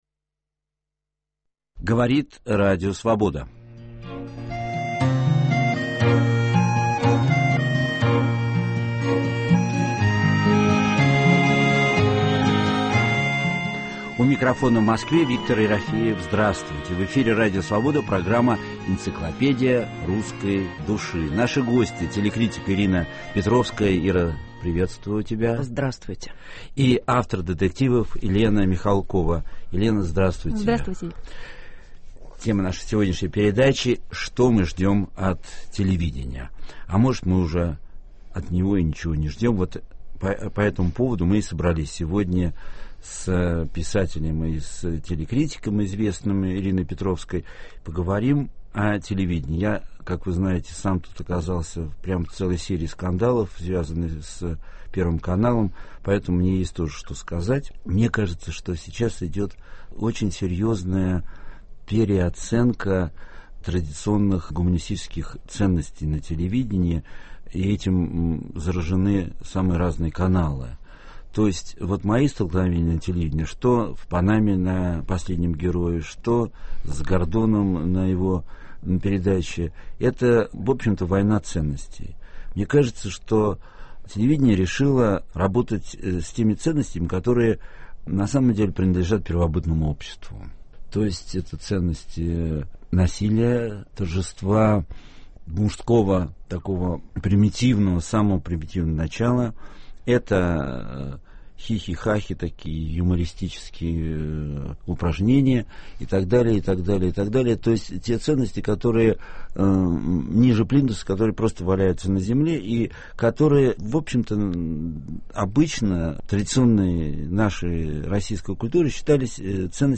Живой опыт самопознания в прямом эфире с участием слушателей, который ведет писатель Виктор Ерофеев. Это попытка определить наши главные ценности, понять, кто мы такие, о чем мы спорим, как ищем и находим самих себя.